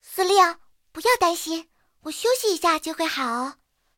SU-26小破修理语音.OGG